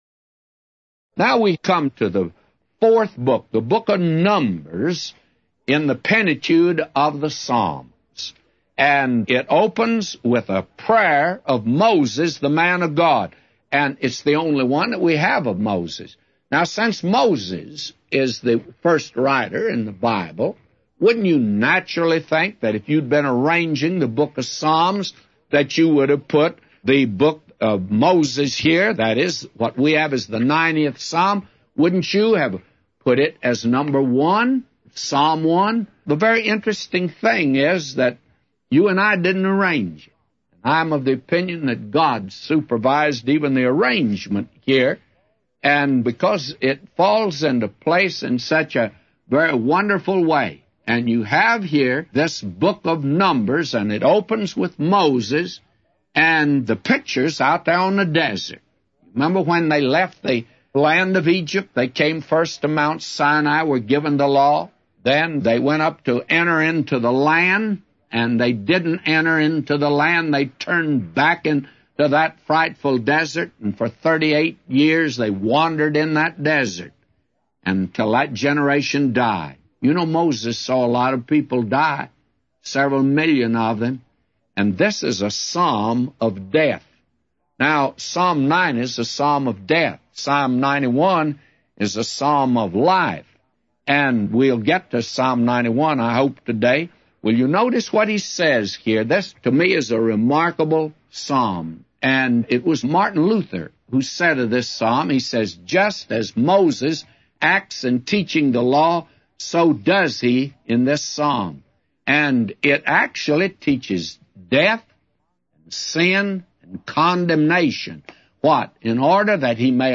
A Commentary By J Vernon MCgee For Psalms 90:1-999